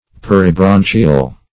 Search Result for " peribranchial" : The Collaborative International Dictionary of English v.0.48: Peribranchial \Per`i*bran"chi*al\, a. (Anat.) Surrounding the branchi[ae]; as, a peribranchial cavity.